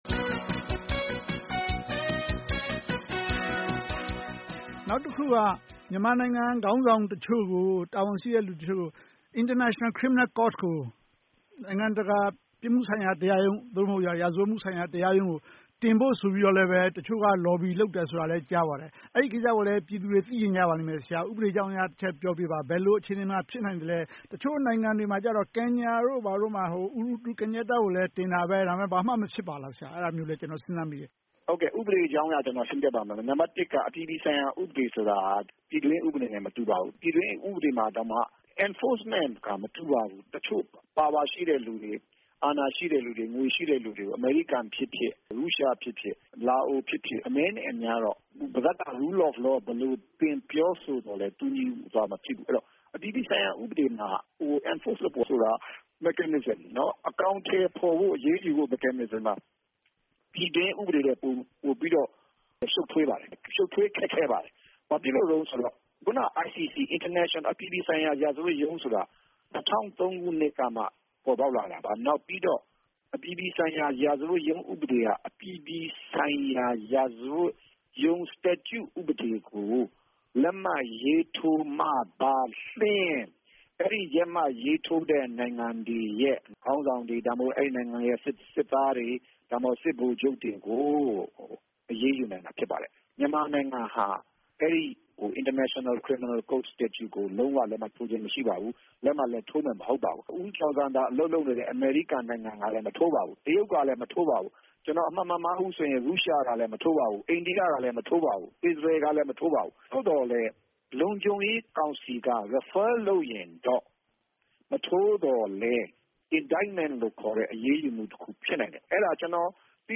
ဆွေးနွေးသုံးသပ်ချက်